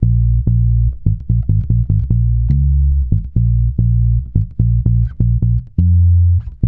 -MM RAGGA A#.wav